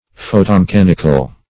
Photomechanical \Pho`to*me*chan"ic*al\, a.